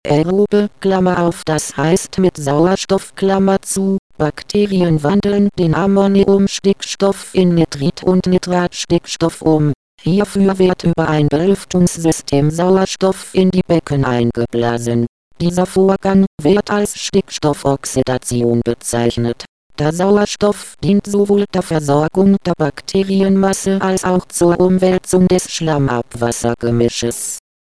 Belebungsbecken
Hierf�r wird �ber ein Bel�ftungssystem Sauerstoff in die Becken eingeblasen.